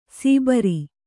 ♪ sībari